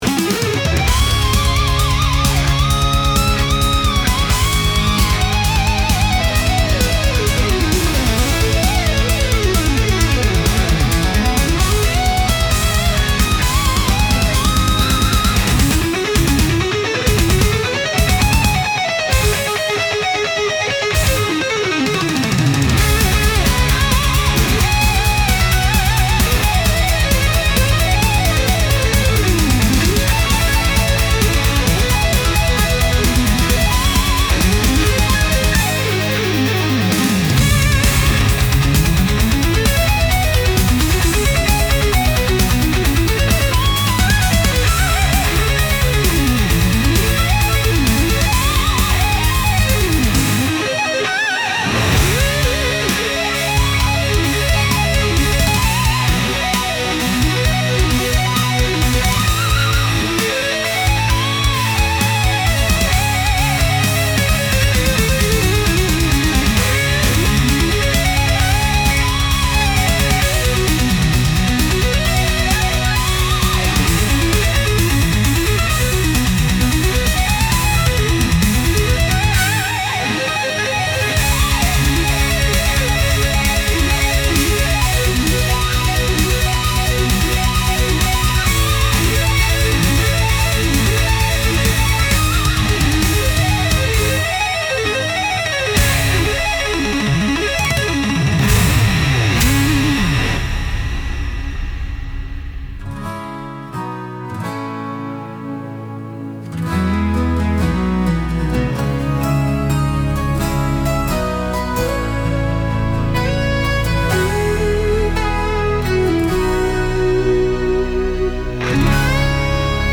I decided to try creating a guitar shred instrumental on Suno, and things got a little out of hand. I repeatedly prompted Suno to the point of absurdity and wound up with an extended, multi-movement composition that was quite impressive in its own way, but (for some reason) ended too abruptly… as in, the tune simply stopped mid-measure.
We’re talking a slathering of massive stereo spread, tight compression and limiting, with a healthy dose of aural exciter tossed in for good measure.
First, there’s the undeniable fact that an AI music generator platform like Suno can wheeze up utterly perfect shred guitar parts that would be virtually — no, make that utterly — impossible for any living breathing human being to play.
Second, with the exception of a few sections where the arrangement mellows a bit, the tempo and intensity of the tune is too frenetic for my taste.